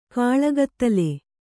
♪ kāḷagattale